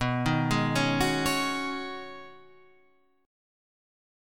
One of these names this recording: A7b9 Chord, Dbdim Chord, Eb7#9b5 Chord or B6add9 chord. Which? B6add9 chord